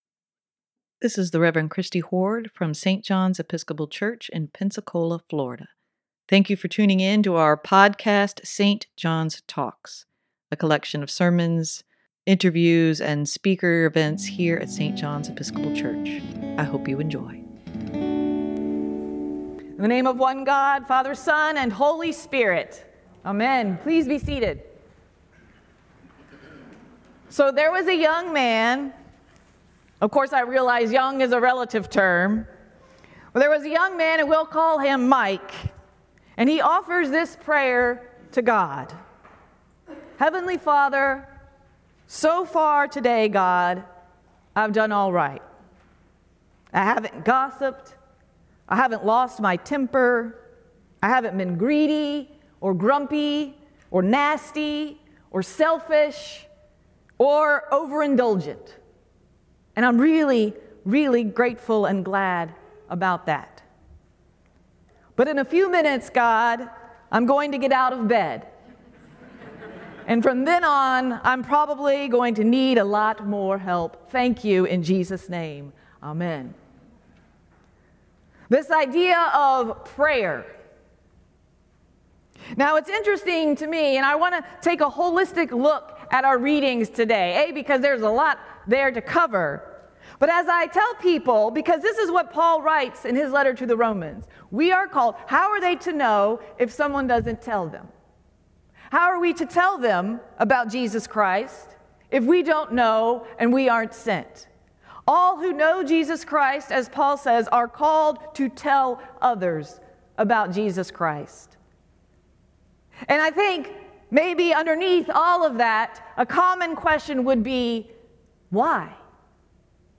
sermon-8-13-23.mp3